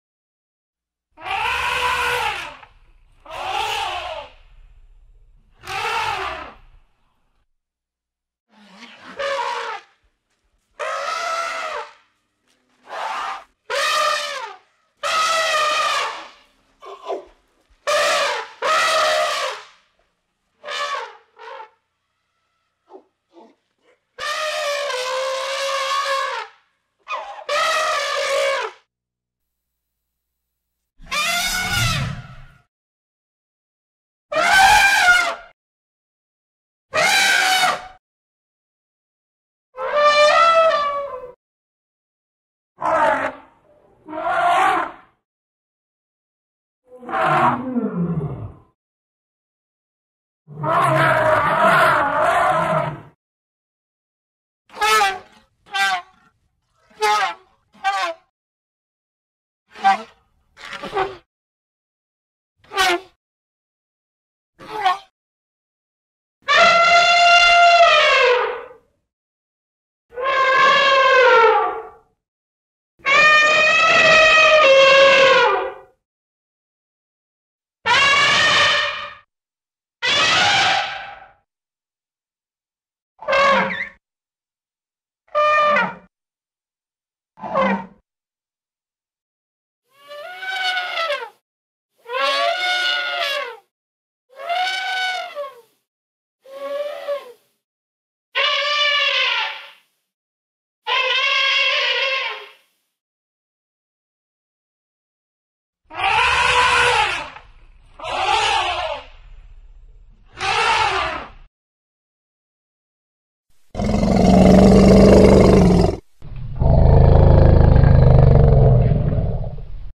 دانلود آهنگ فیل 1 از افکت صوتی انسان و موجودات زنده
دانلود صدای فیل 1 از ساعد نیوز با لینک مستقیم و کیفیت بالا
جلوه های صوتی